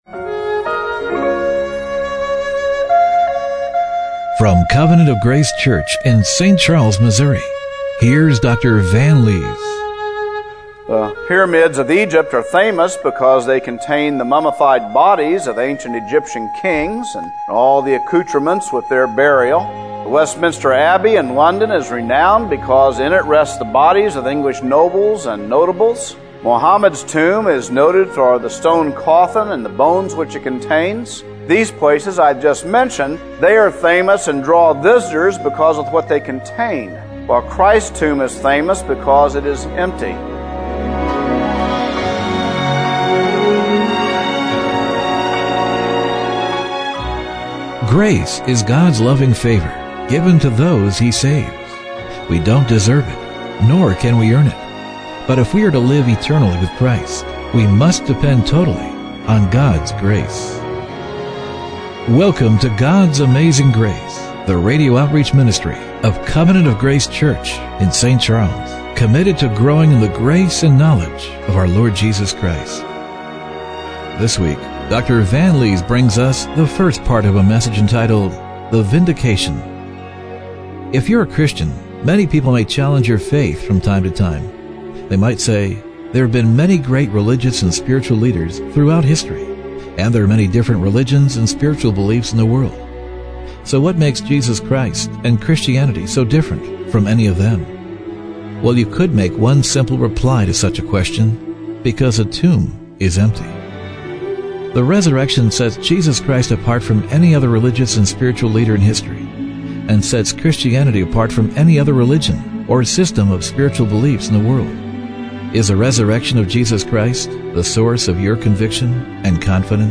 Radio Broadcast Is the resurrection of Jesus Christ the source of your conviction and confidence?